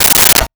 Plastic Plate 04
Plastic Plate 04.wav